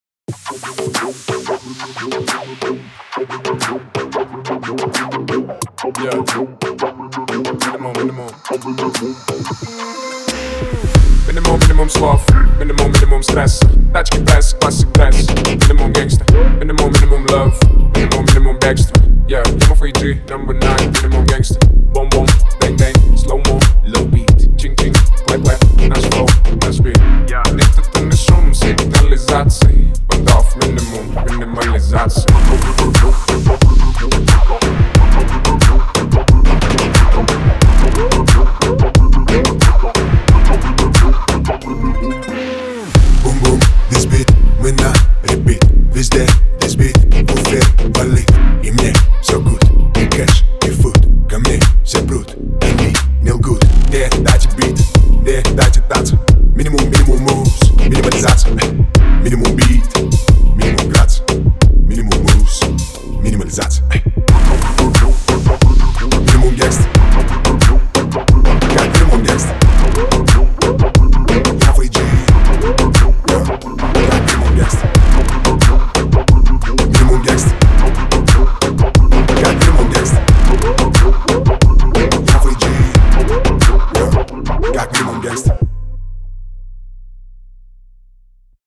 دانلود آهنگ ساب دار روسی مخصوص ماشین